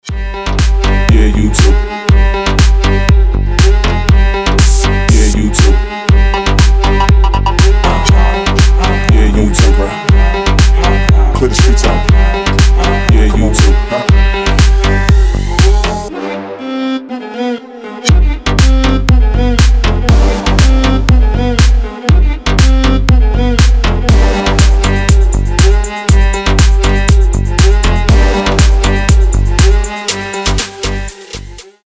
мужской голос
deep house
восточные мотивы
Bass House
G-House